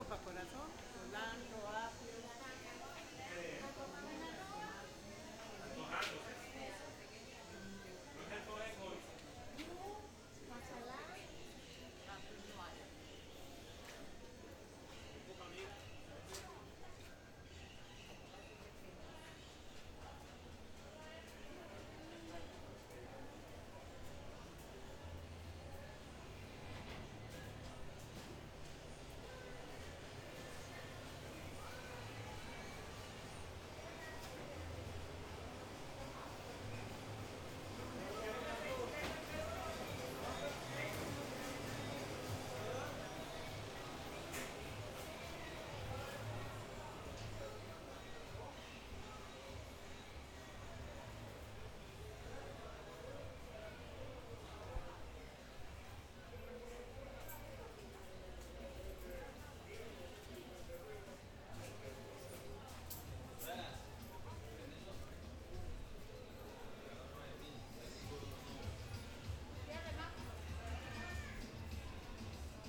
Mercado de Limón LIMÓN